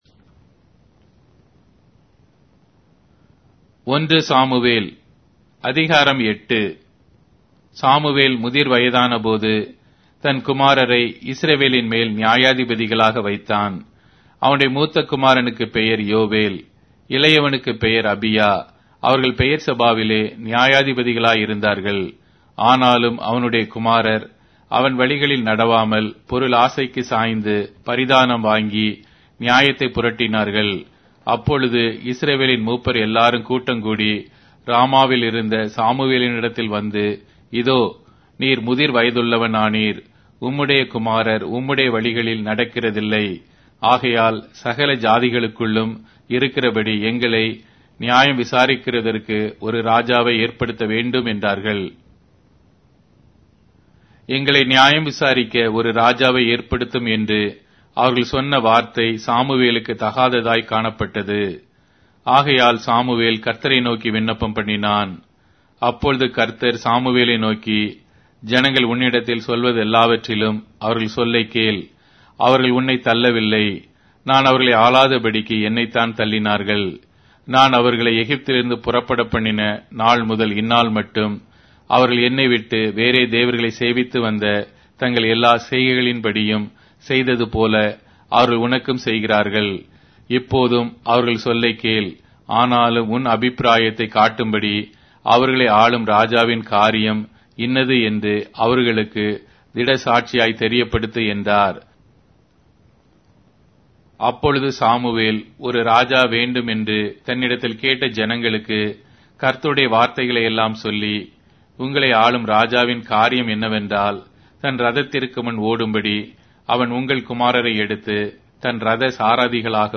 Tamil Audio Bible - 1-Samuel 24 in Bnv bible version